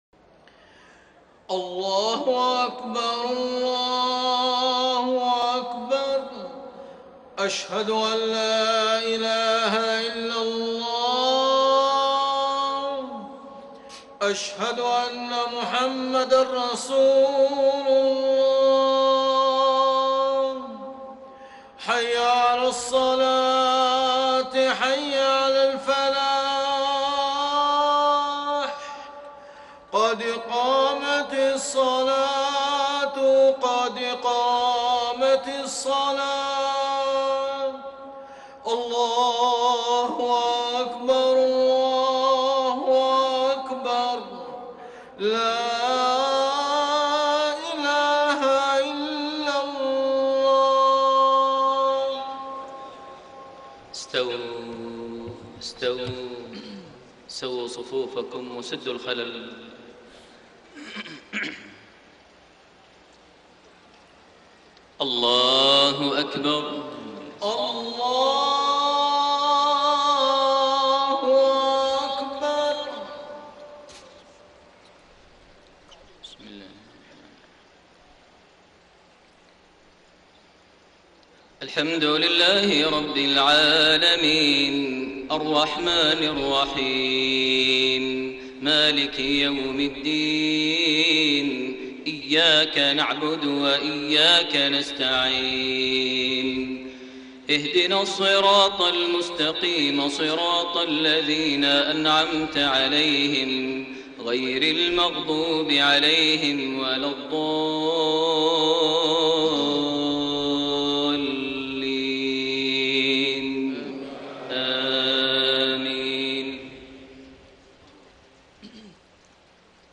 صلاة المغرب 6 شعبان 1433هـ من سورة النازعات 15-46 > 1433 هـ > الفروض - تلاوات ماهر المعيقلي